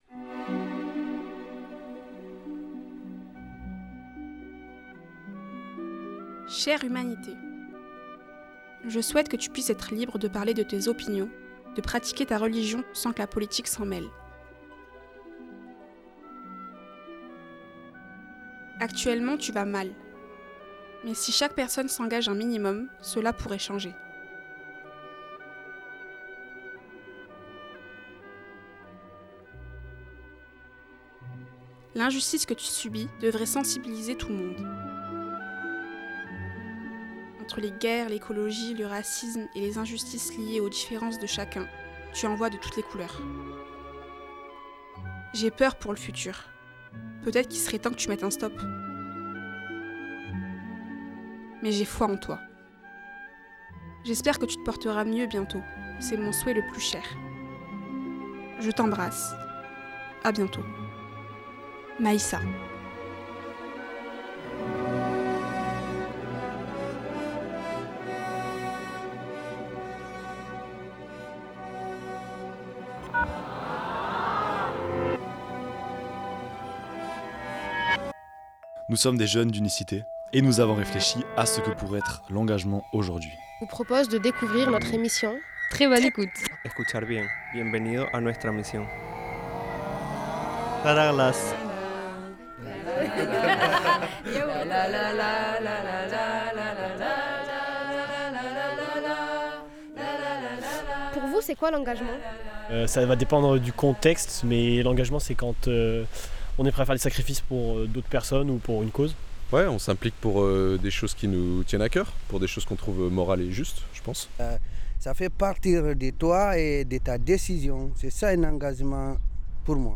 Cette émission a été réalisée avec des jeunes du groupe Melting Pot de l'association Uniscité. Ensemble, ils ont voulu parler d'engagement, de leurs combats quotidiens et de la place qu'ils souhaitent prendre dans ce monde pour défendre une humanité en péril.